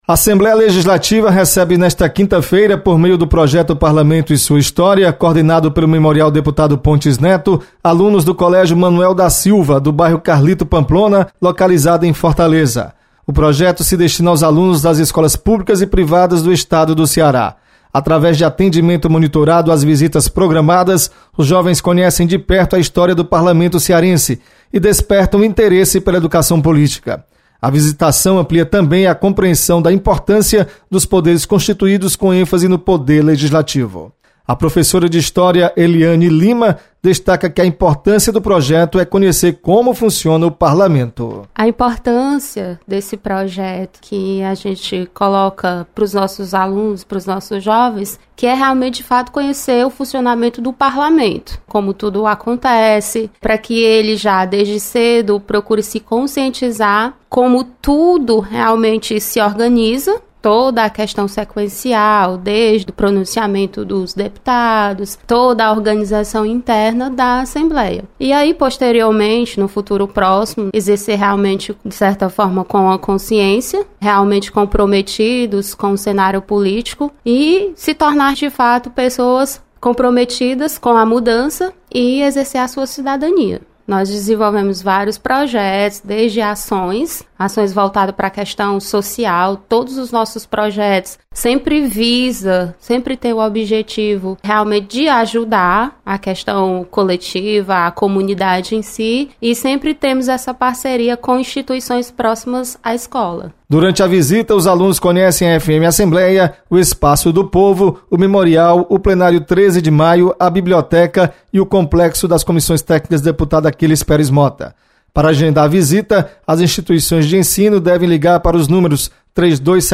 Memorial Pontes neto recebe alunos do bairro Carlito Pamplona. Repórter